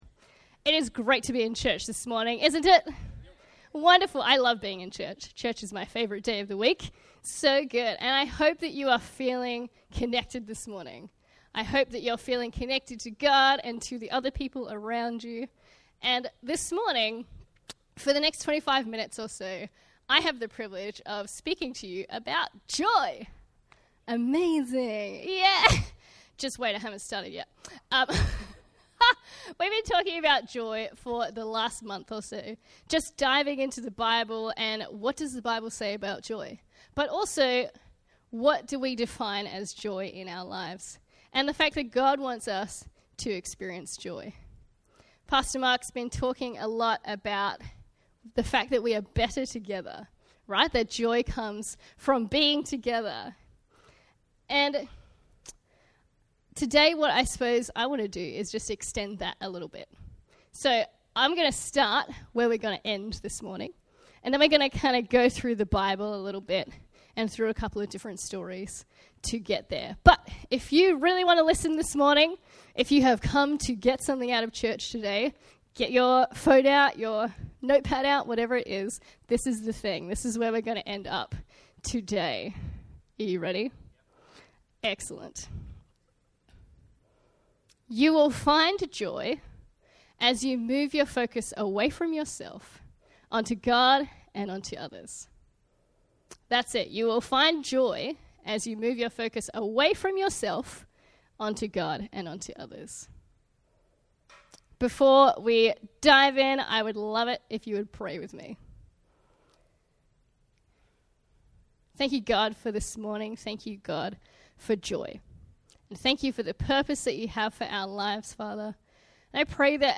A message from the series "Joy." Joy is found when our focus is shifted from ourselves to God and to others.